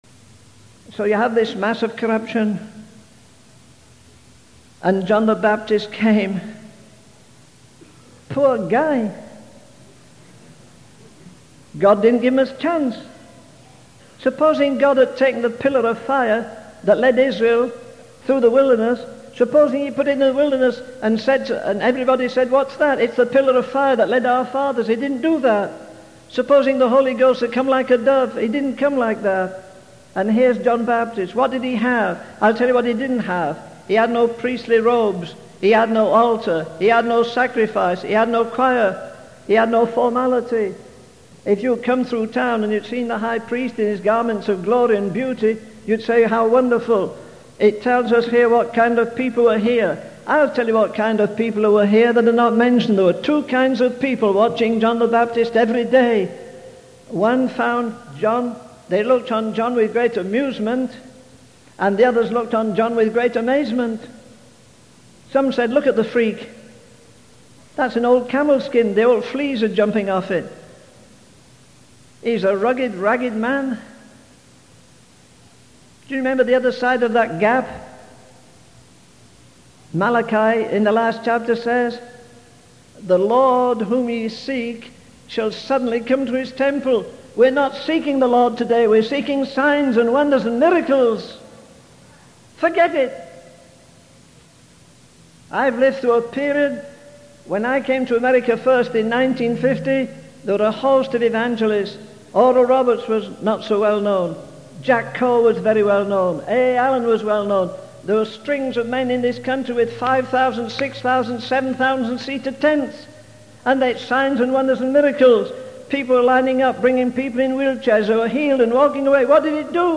In this sermon, the preacher emphasizes the three things that are missing in modern preaching: immensity, intensity, and eternity.